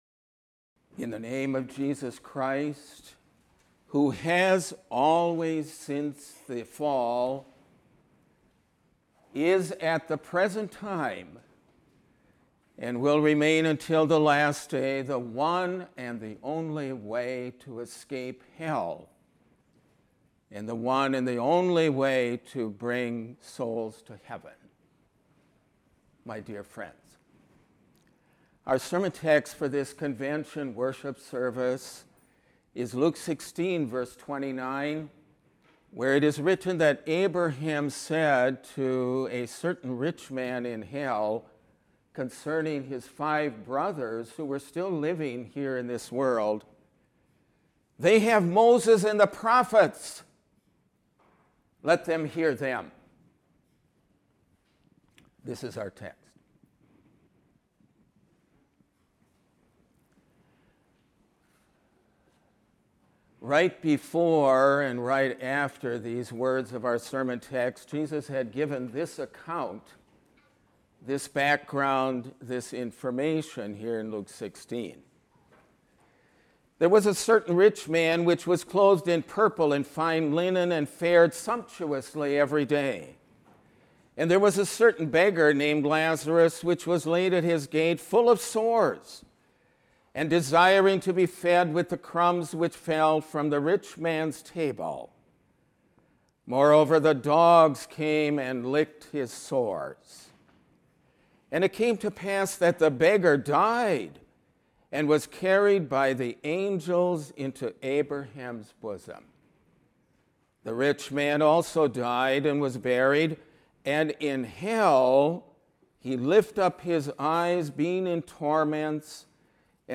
Sermon-6-28-15-Conv-Sun.mp3